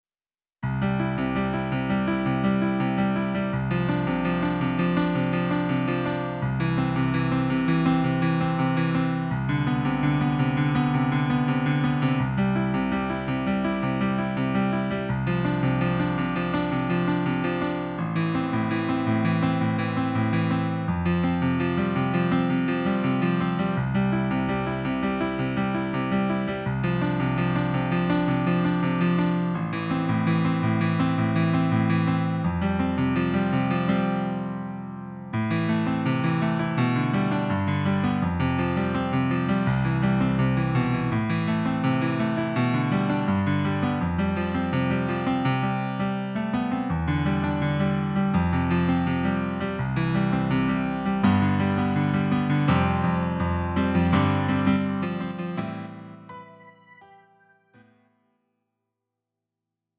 음정 -1키 3:45
장르 가요 구분 Lite MR